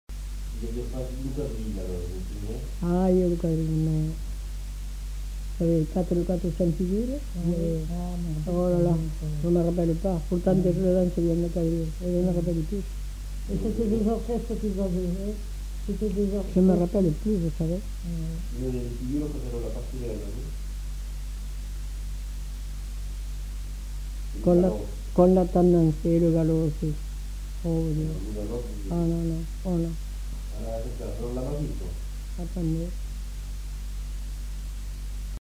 Aire culturelle : Haut-Agenais
Lieu : Cancon
Genre : témoignage thématique